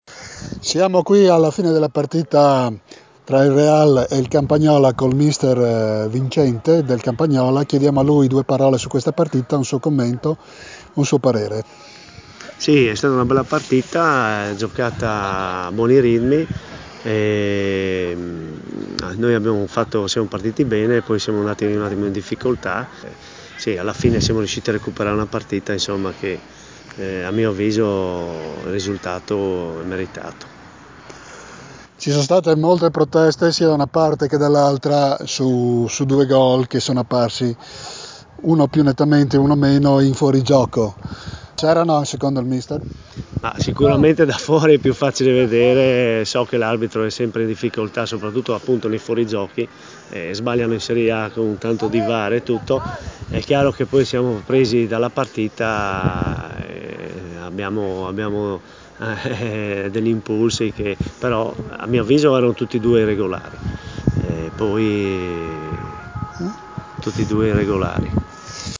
Proponiamo l’intervista realizzata al termine di Real-Campagnola, vinta 3-2 dagli ospiti